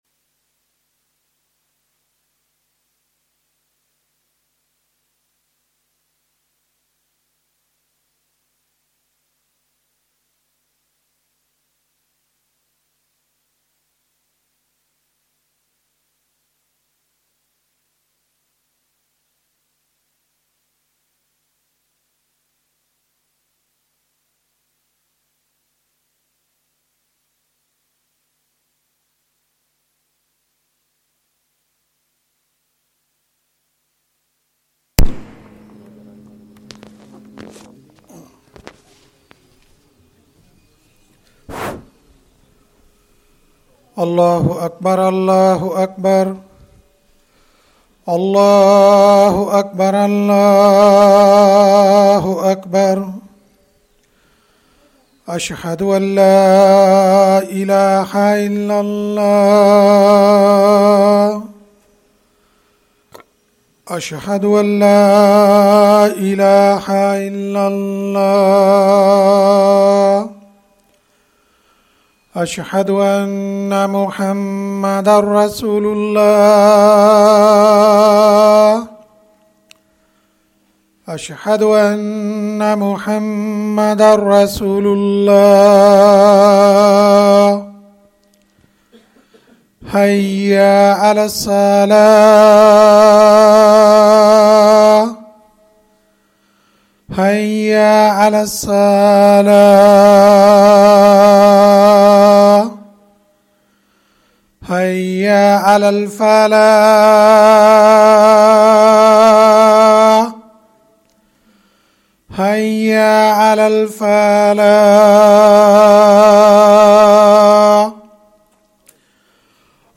Isha Salah and Urdu Talk